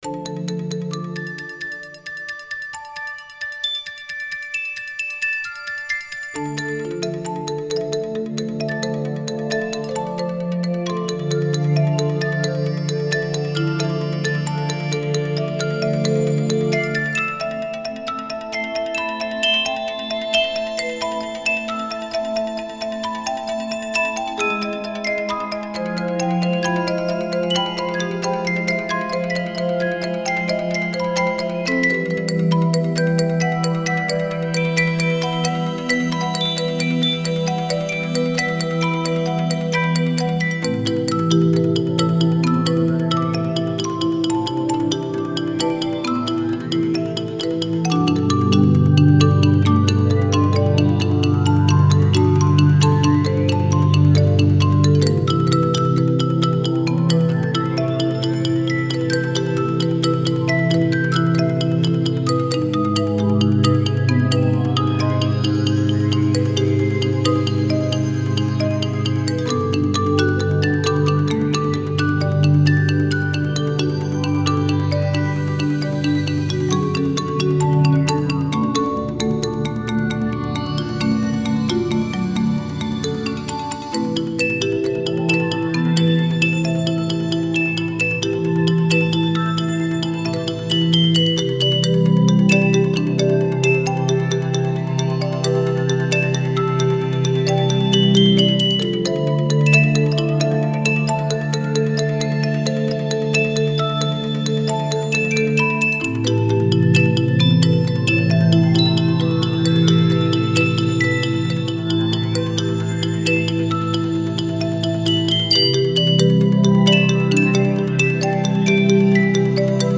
Духовная музыка Медитативная музыка